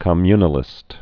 (kə-mynə-lĭst)